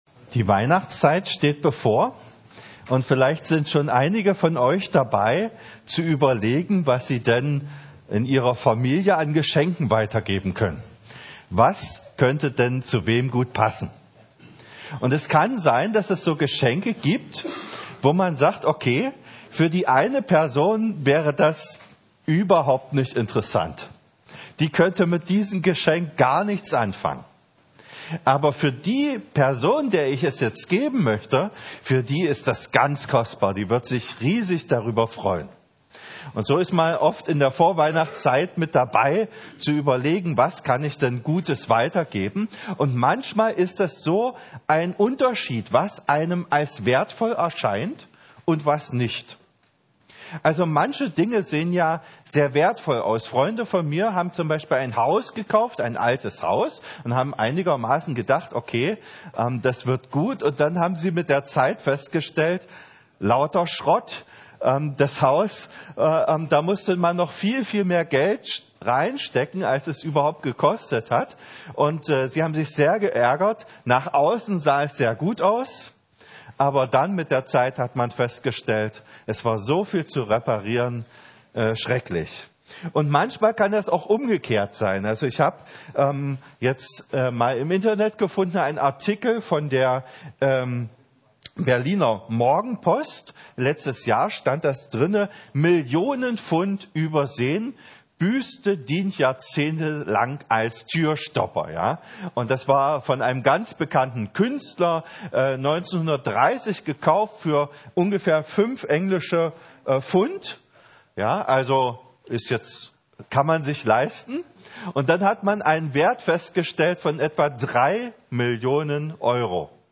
Predigten | EG-Meidling 5/54